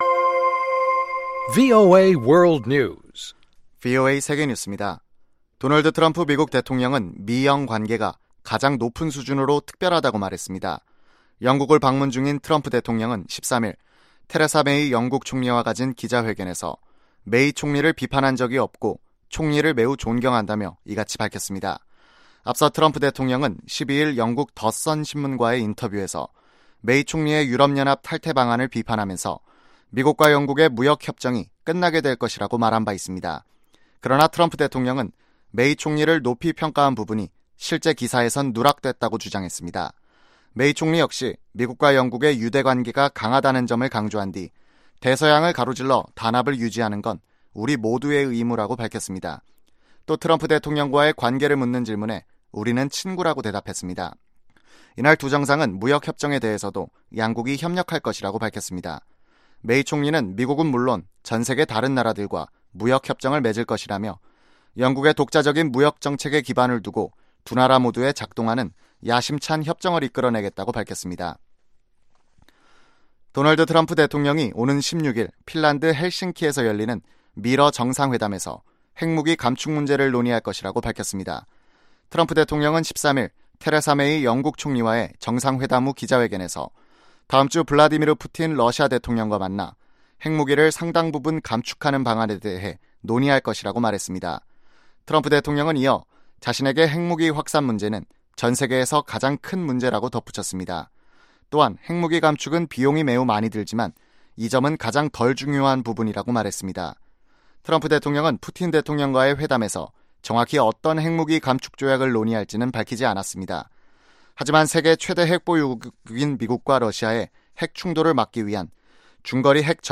VOA 한국어 아침 뉴스 프로그램 '워싱턴 뉴스 광장' 2018년 7월 14일 방송입니다. ‘지구촌 오늘’에서는도널드 트럼프 미국 대통령이 영국 정부의 유럽연합(EU) 탈퇴 방식을 강하게 비판했다는 소식, ‘아메리카 나우’에서는 미국 민권운동의 기폭제가 된 것으로 평가되는 에멧 틸 살해 사건을 연방 법무부가 다시 수사할 것이라는 이야기 전해드립니다.